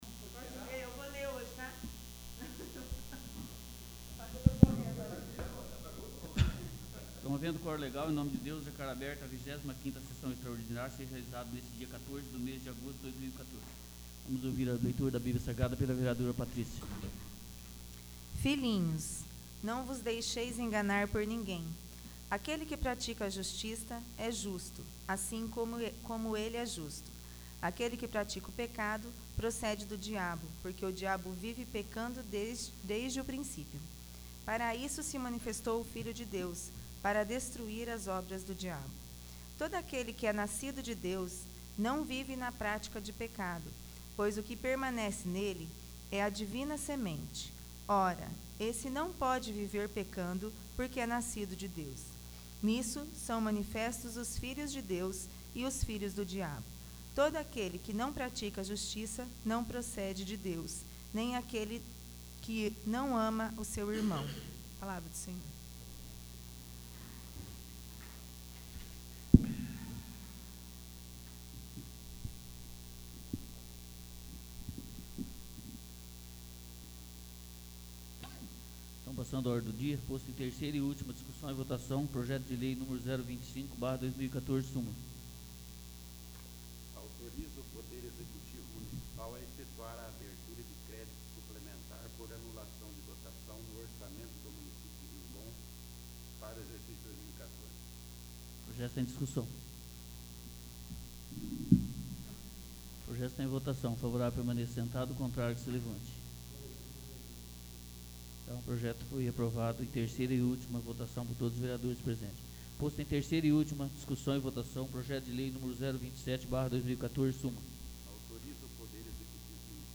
25º. Sessão Extraordinária